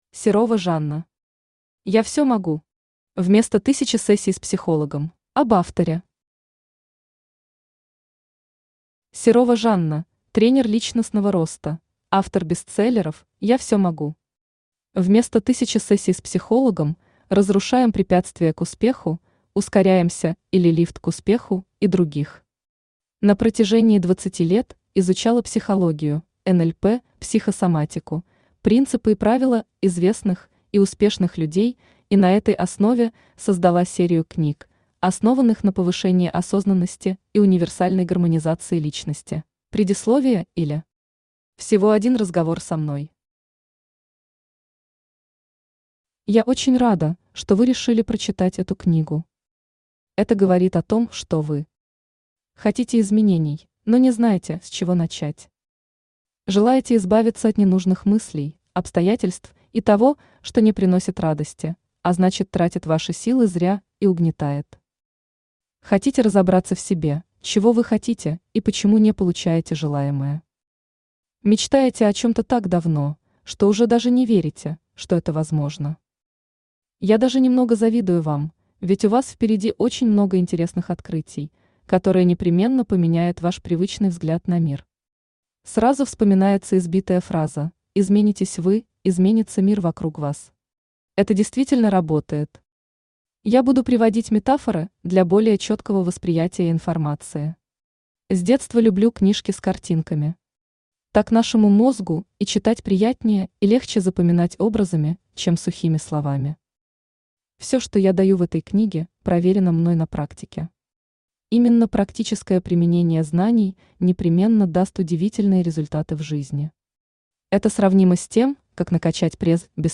Вместо 1000 сессий с психологом Автор Серова Жанна Читает аудиокнигу Авточтец ЛитРес.